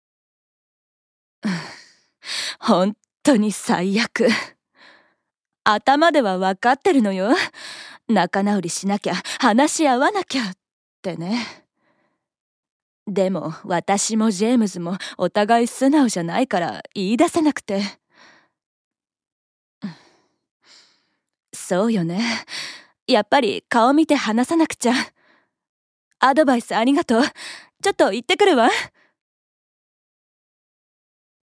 ◆恋に悩める女性◆